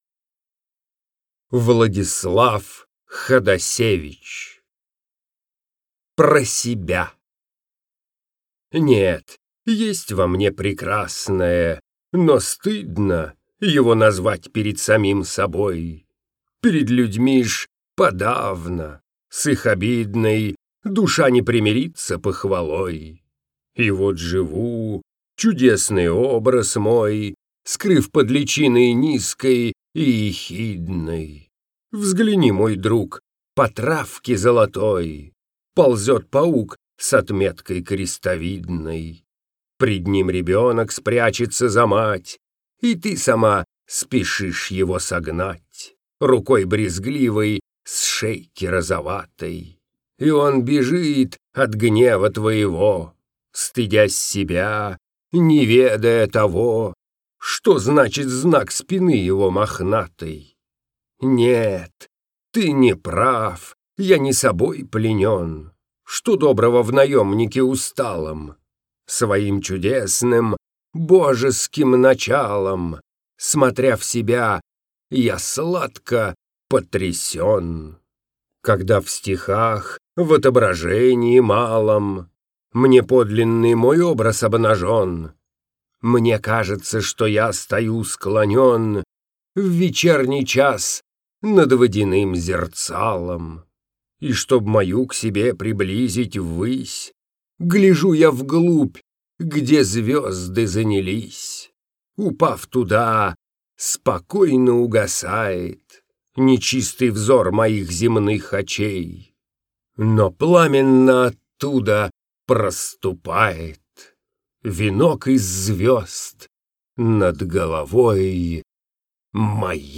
1. «Владислав Ходасевич – Про себя (Хвост читает)» /
Hodasevich-Pro-sebya-Hvost-chitaet-stih-club-ru.mp3